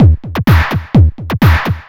DS 127-BPM A6.wav